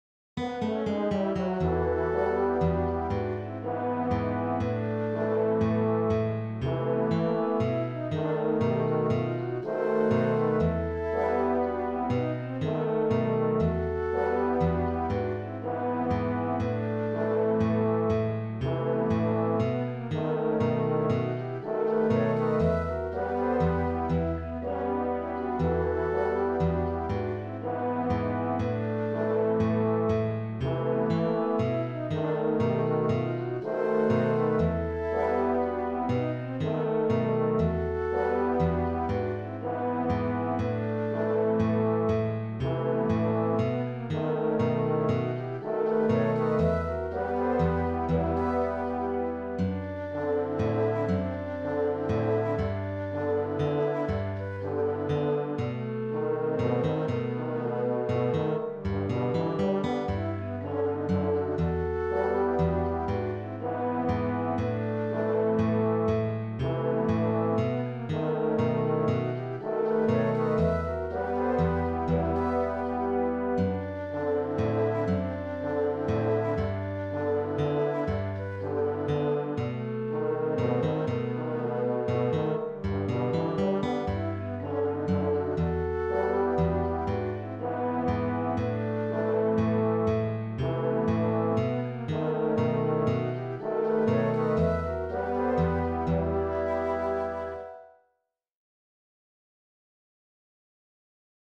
arranged for four guitars
The range goes to a high E, first string, twelfth fret.